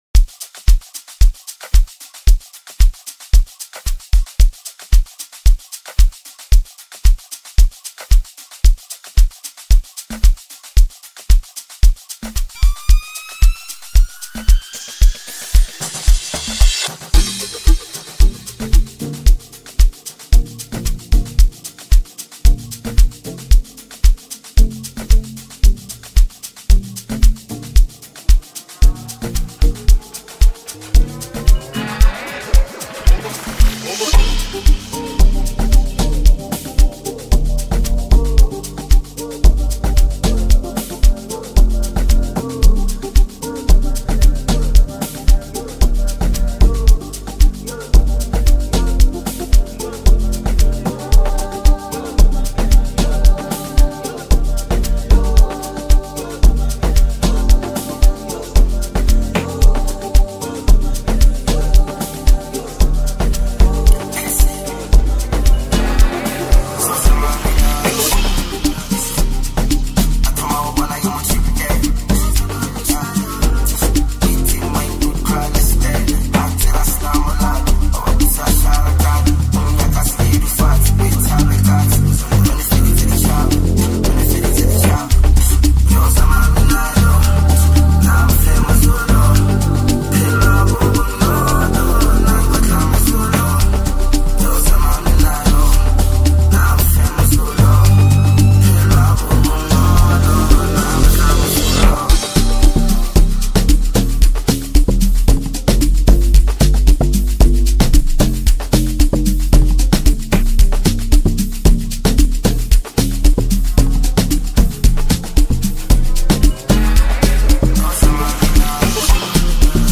• Genre: Amapiano